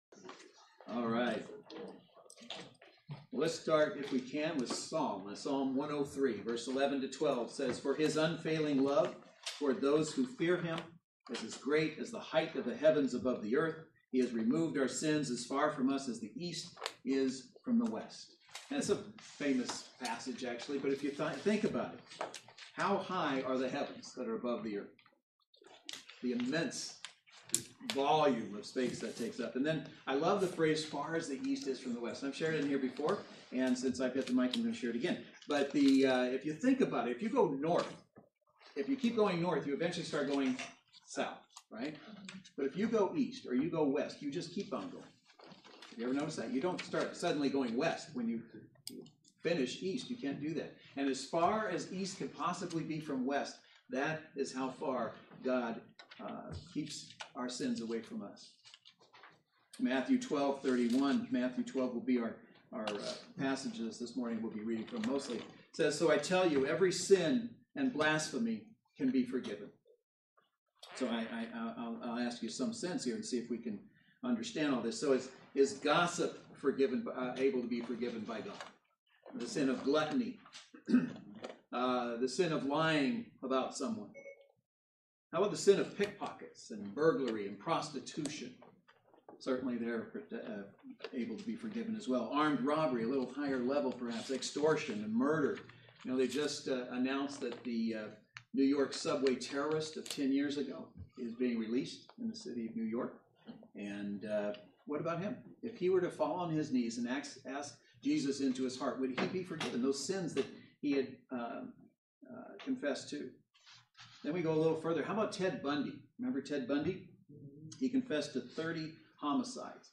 Service Type: Saturday Worship Service Speaker